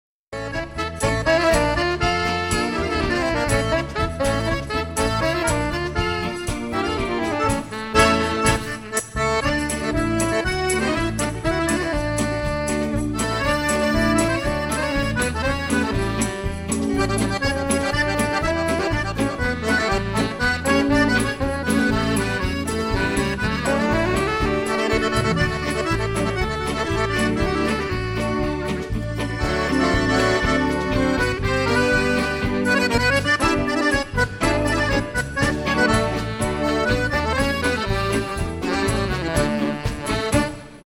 paso